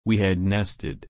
/nɛst/